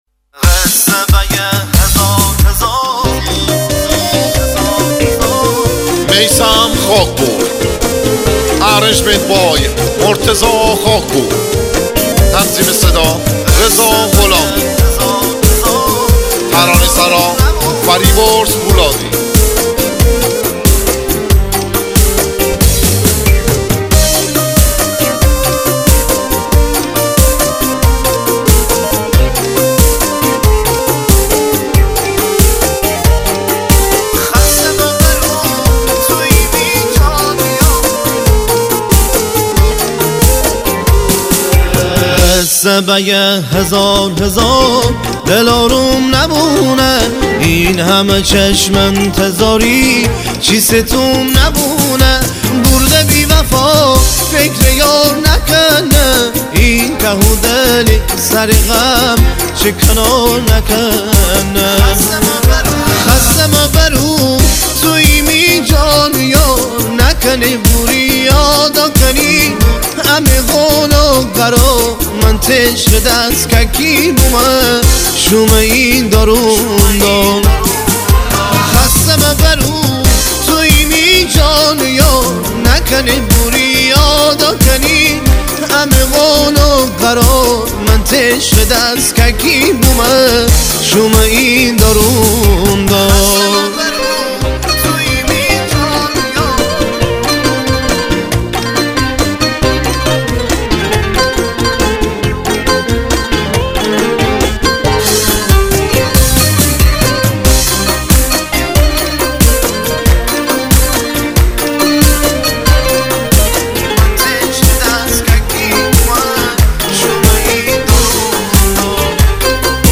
آهنگ مازندرانی جدید زیبا و فوق العاده
آهنگ شاد